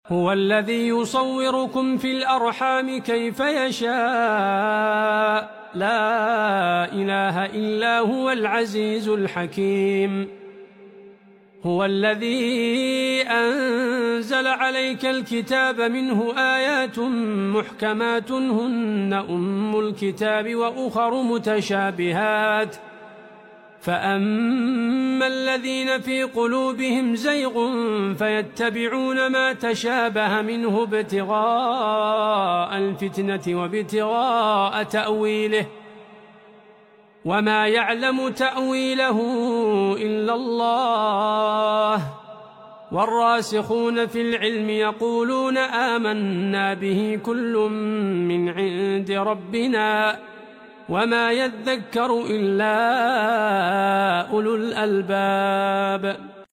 Listen to the wonderful recitation of the Qur'an